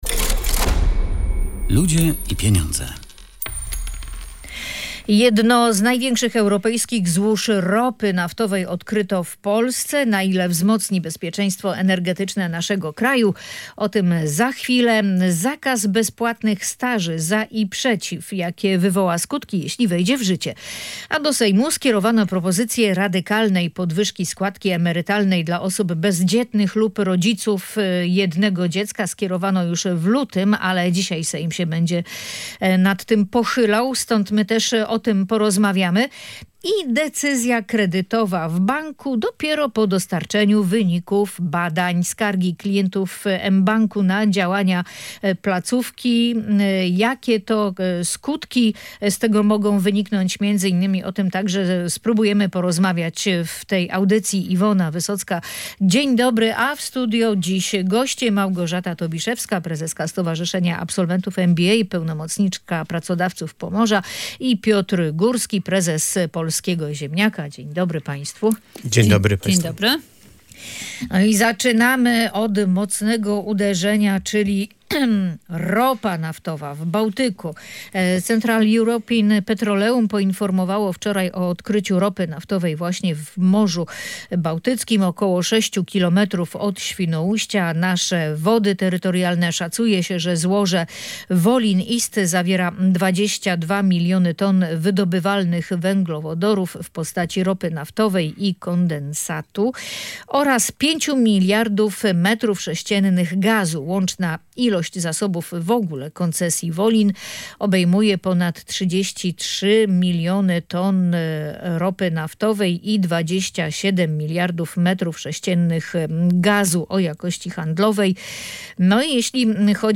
Na ten temat w audycji „Ludzie i Pieniądze” dyskutowali